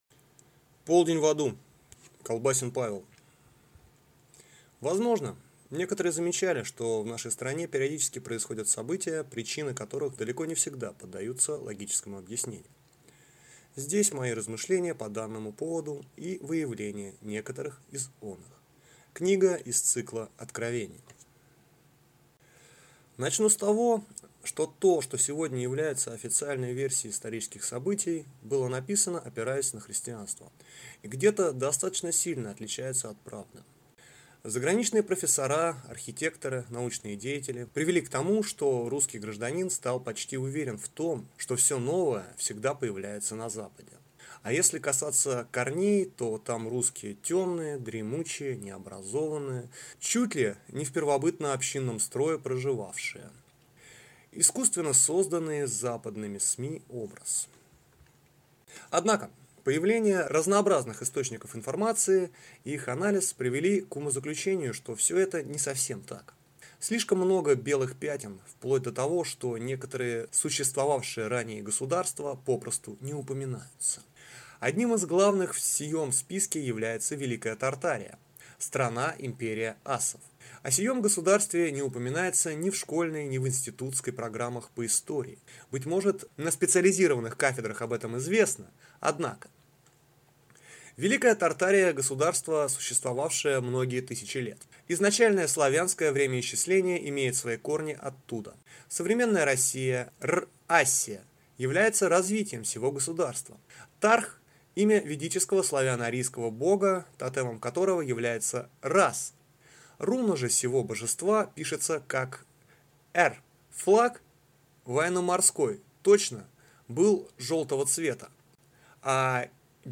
Аудиокнига Полдень в Аду | Библиотека аудиокниг